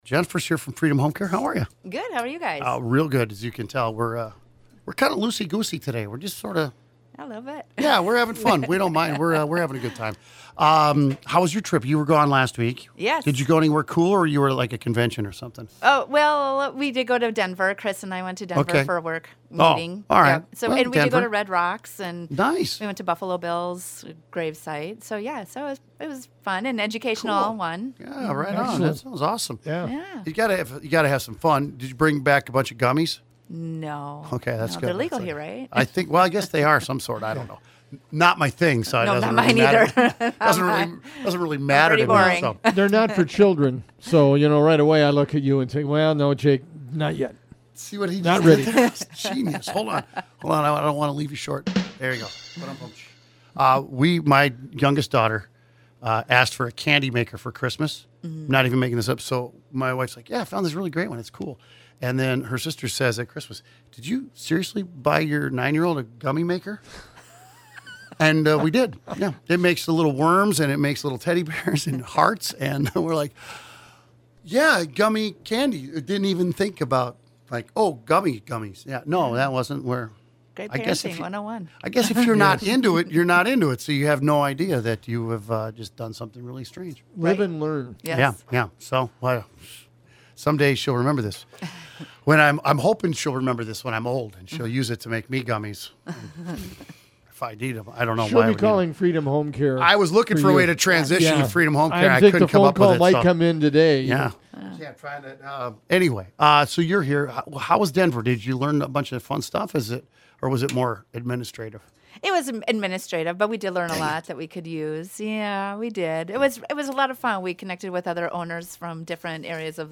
Live interviews are aired on Wednesday mornings between 9:20 a.m. to 9:35 a.m. on KTOE 1420 AM